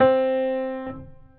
Piano - Hard.wav